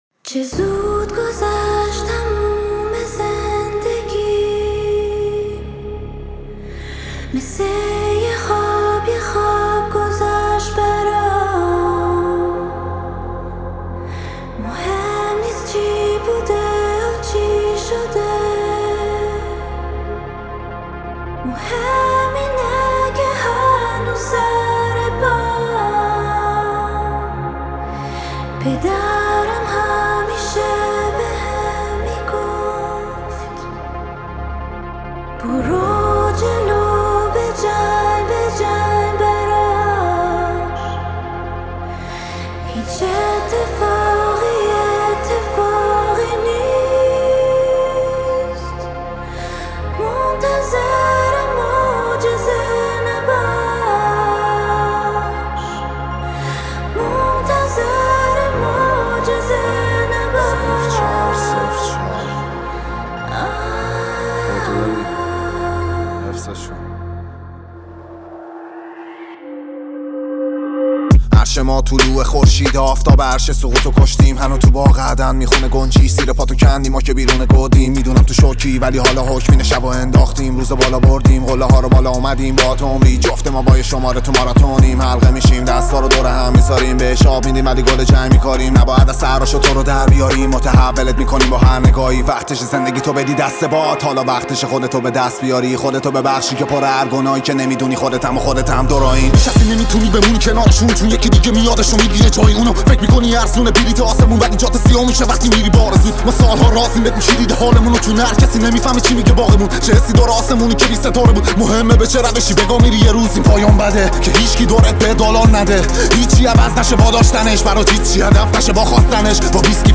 عالی واقعا آرامش میگیره آدم با صدای زنه